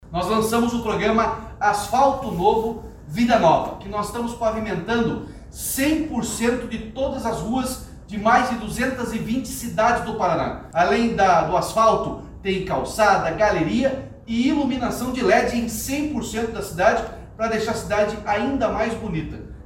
Sonora do governador Ratinho Junior sobre a liberação de recursos para 10 municípios através do programa Asfalto Novo, Vida Nova